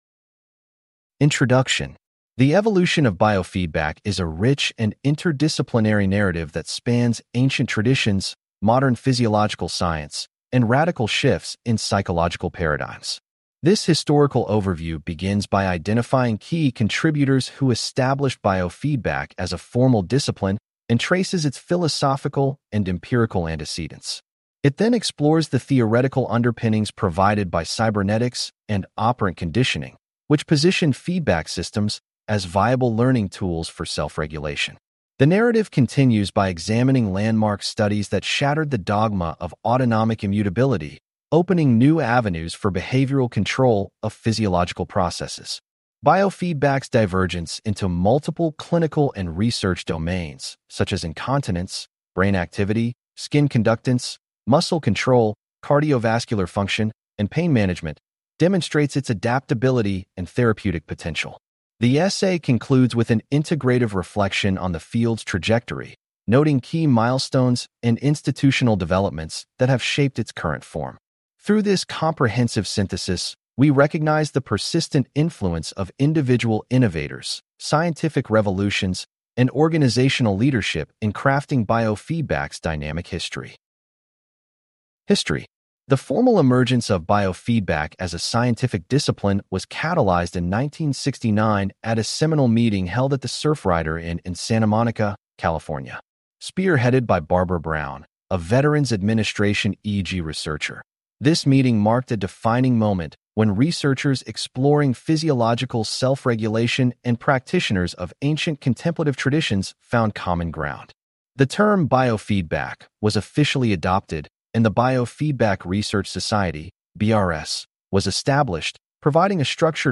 🎧 Listen to the Chapter Lecture History depends on who writes it and who survives it.